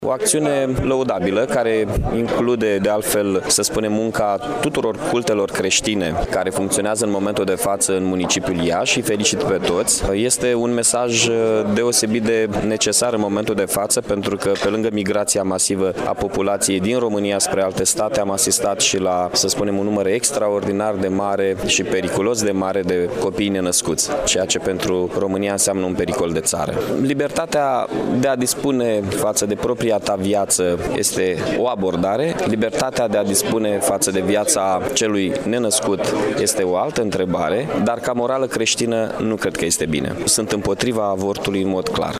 La Marşul pentru Viaţă a fost prezent şi primarul Mihai Chirica. El a atras atenţia că numărul mare de avorturi reprezintă un pericol pentru ţara noastră şi a militat pentru dreptul la viaţă al copiilor nenăscuţi :